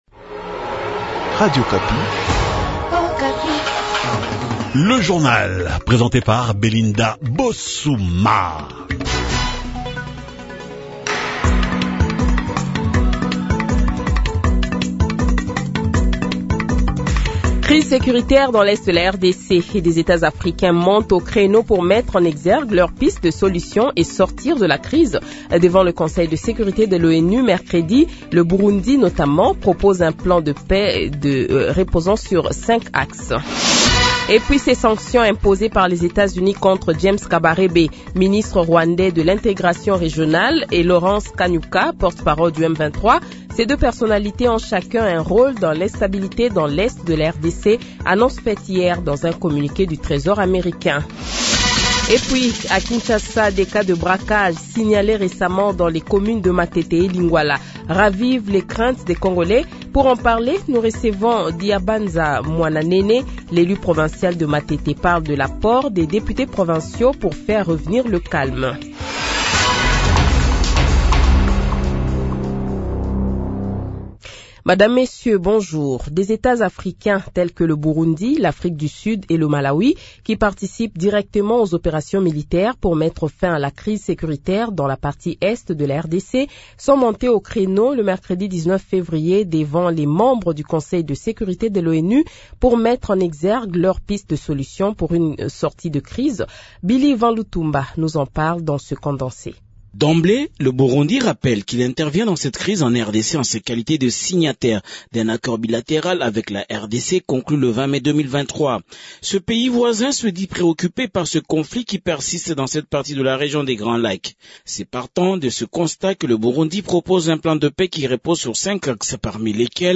Le Journal de 7h, 21 Fevrier 2025 :